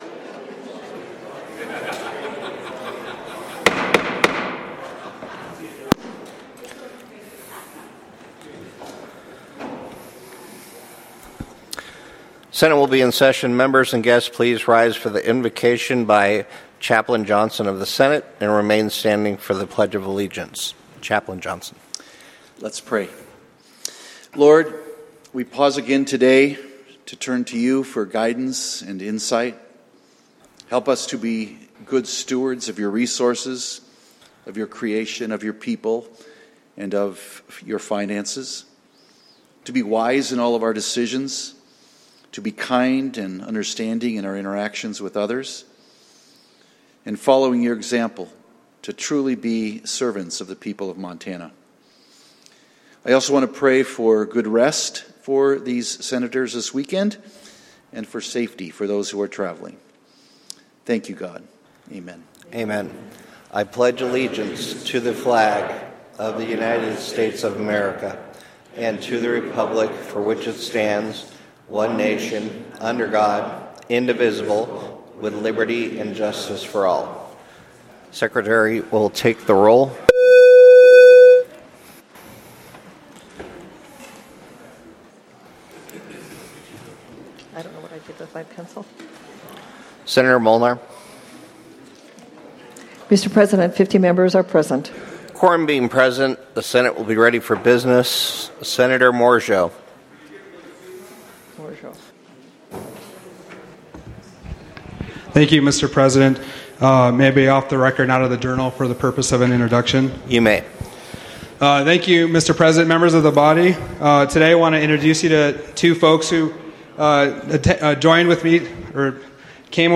Senate Floor Session